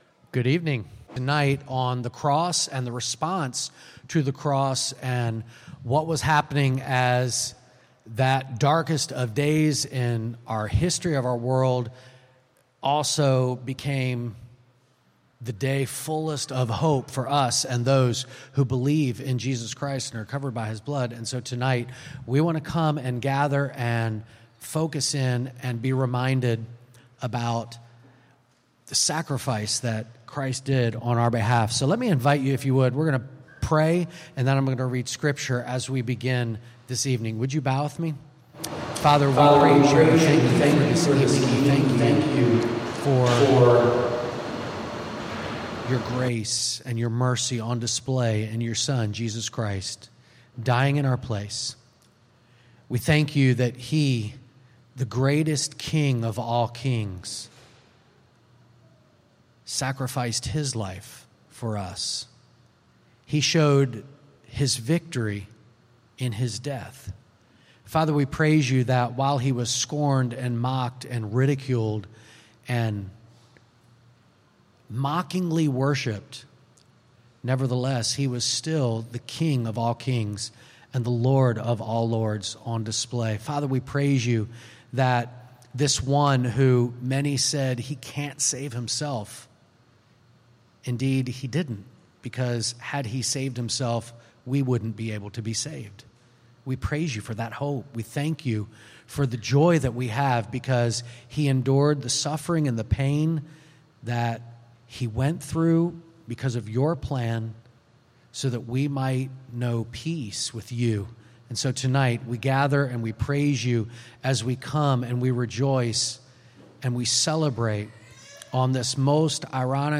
Sermons | Great Commission Baptist Church
Easter Service – Resurrection Sunday